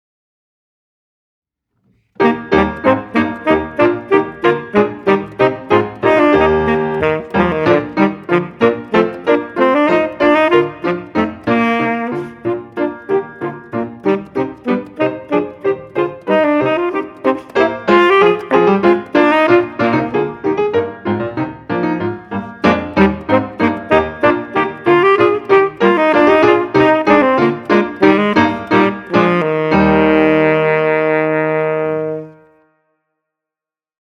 Saxophone Ténor et Piano